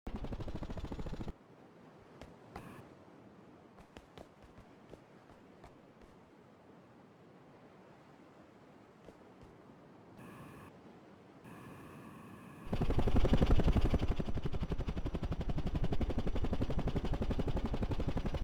Under certain conditions, the drivable helicopter will incorrectly play its engine sound at maximum volume when coming to a standstill.
The helicopters engine sound would slowly decrease until it fully stopped and became completely quiet.